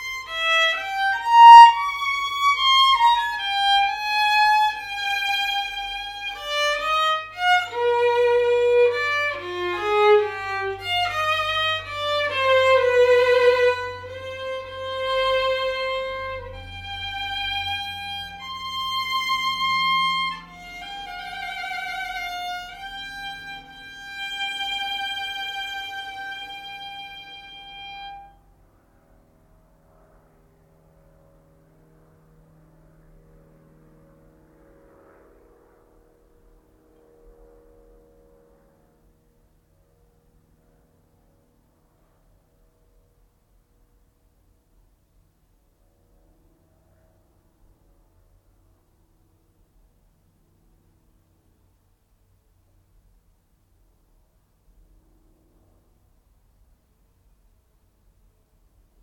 A rather nice moment at the end of a (not very good) take of Händel